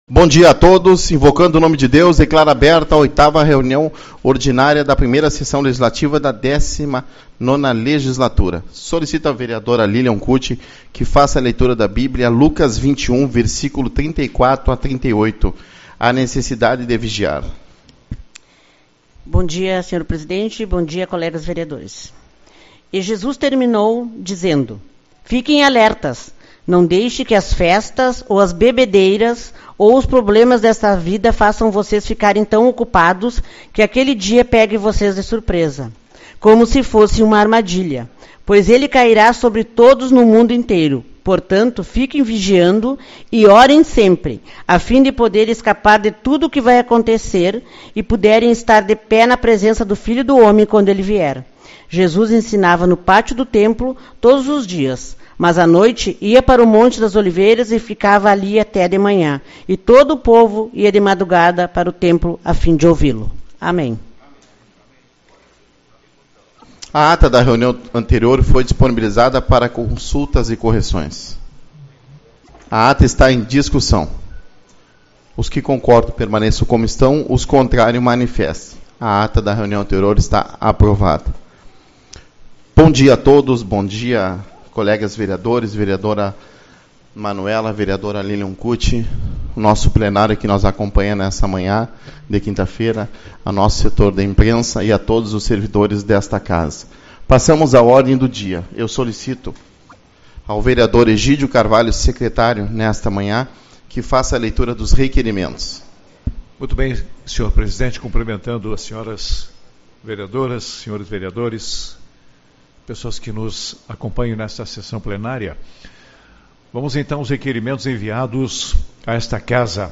27/02- Reunião Ordinária